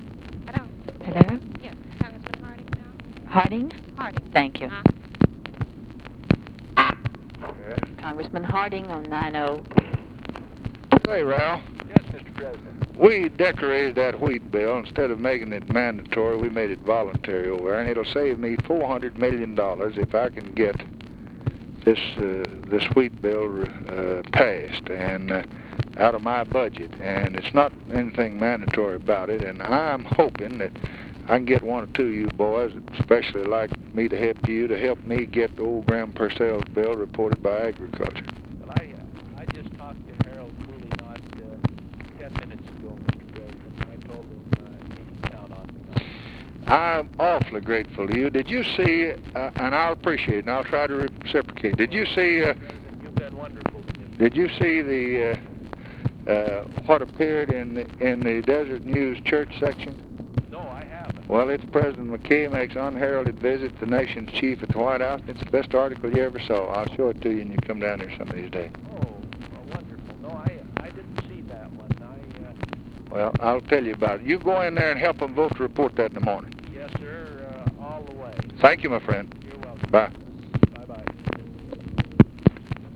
Conversation with RALPH HARDING, March 9, 1964
Secret White House Tapes